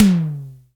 Index of /90_sSampleCDs/Roland L-CD701/DRM_Analog Drums/TOM_Analog Toms
TOM SIMM T8.wav